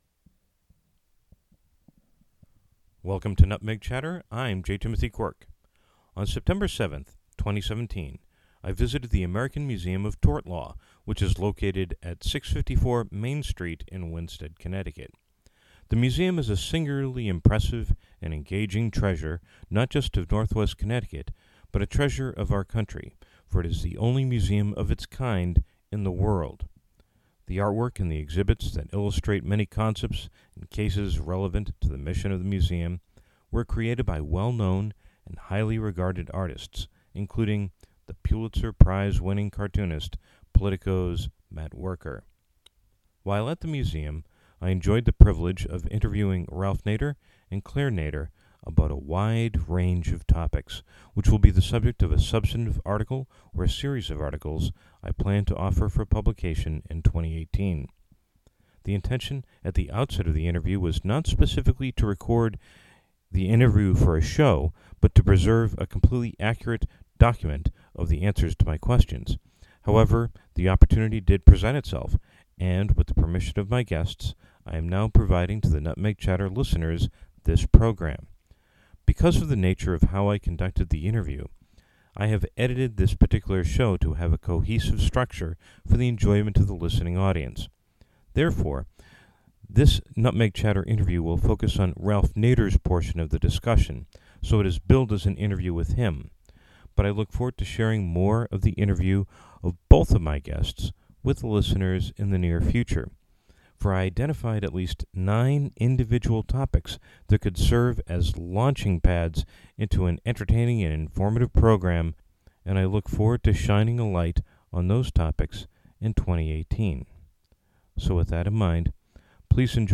Because of the nature of how I conducted the interview, I have edited this particular show to have a cohesive structure for the enjoyment of the listening audience.